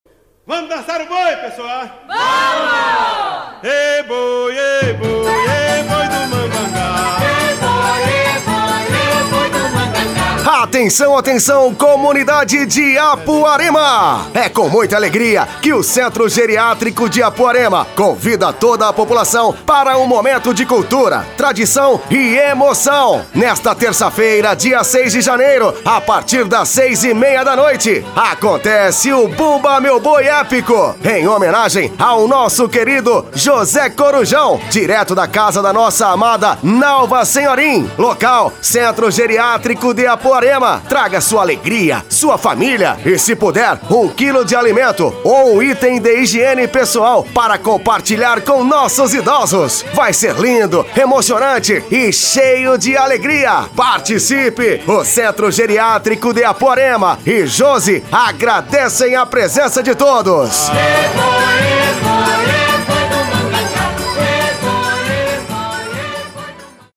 FESTA DO BUMBA MEU BOI: